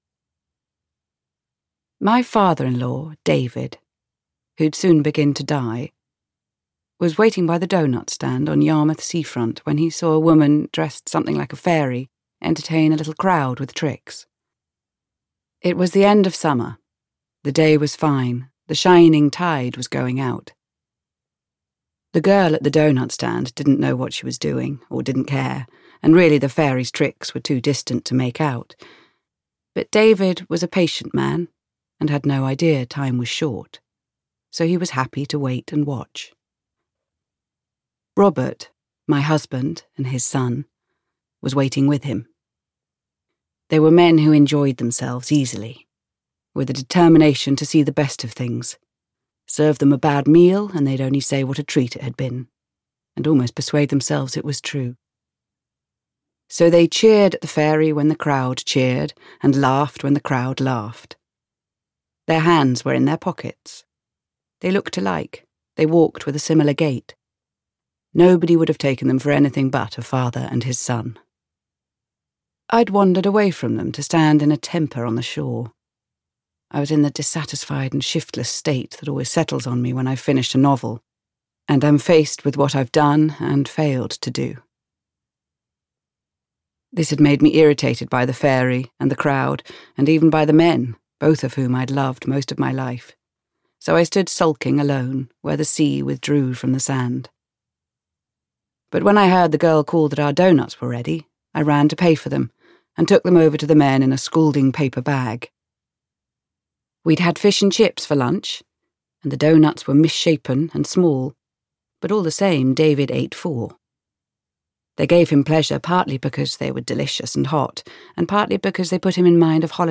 Audiobook sample